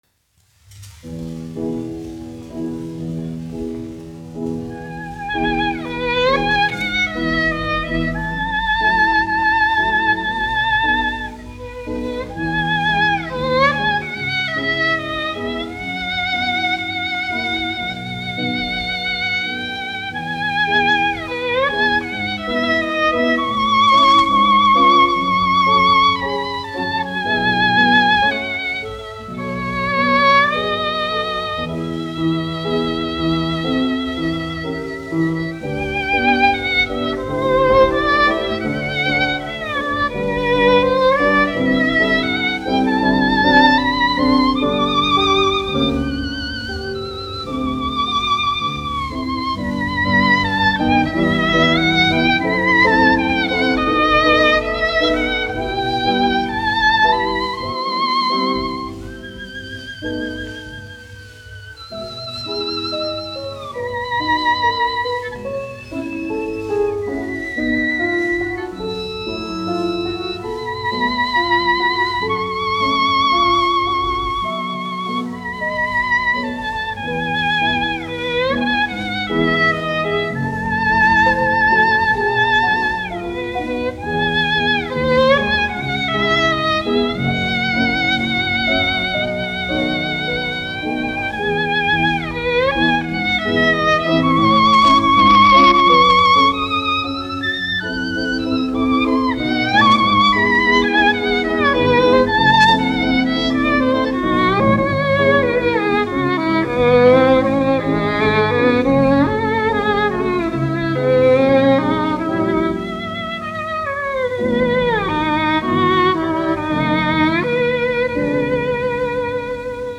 1 skpl. : analogs, 78 apgr/min, mono ; 25 cm
Vijoles un klavieru mūzika
Latvijas vēsturiskie šellaka skaņuplašu ieraksti (Kolekcija)